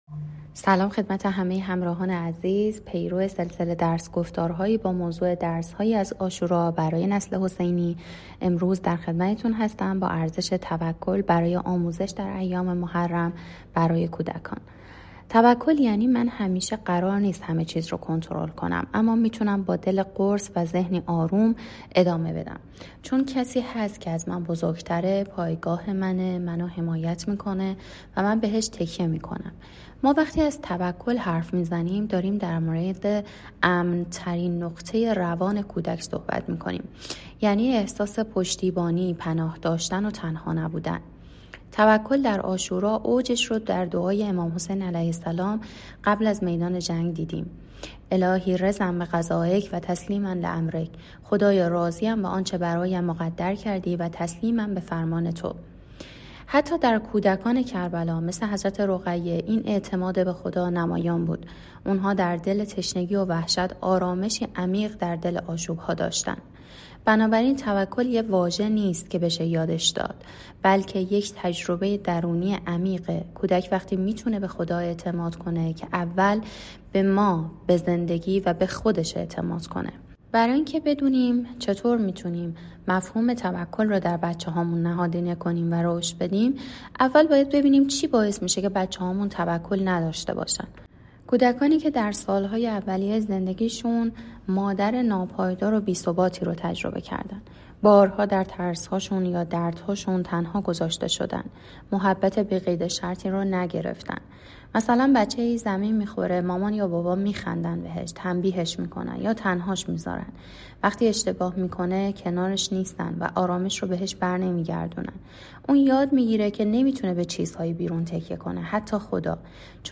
درس‌گفتار‌هایی